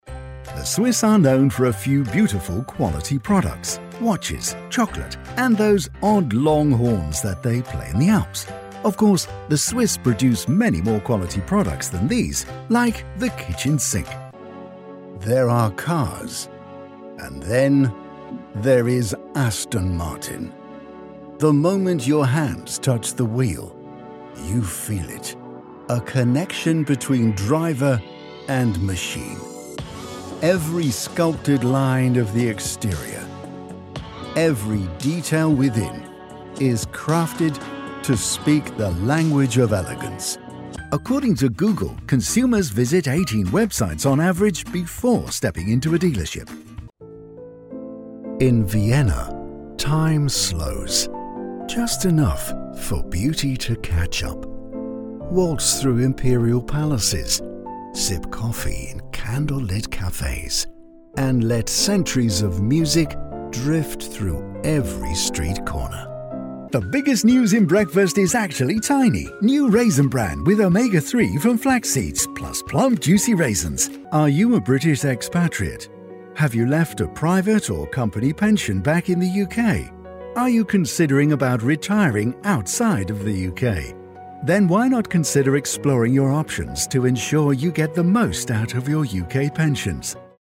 Male
British English (Native)
Assured, Authoritative, Character, Confident, Corporate, Engaging, Friendly, Natural, Smooth, Warm, Versatile
Microphone: Rode NT1a